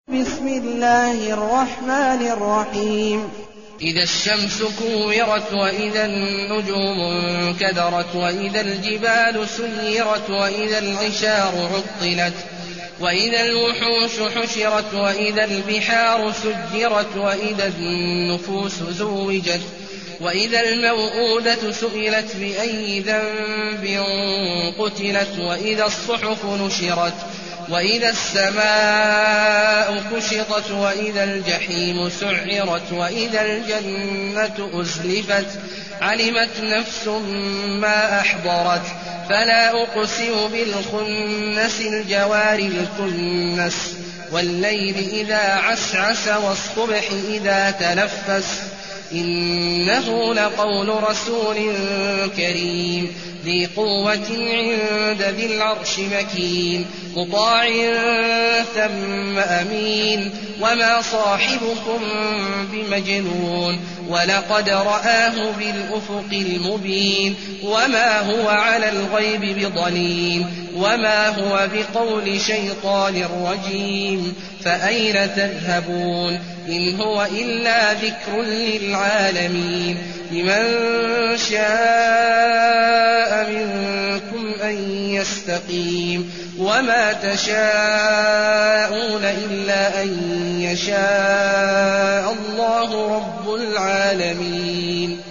المكان: المسجد الحرام الشيخ: عبد الله عواد الجهني عبد الله عواد الجهني التكوير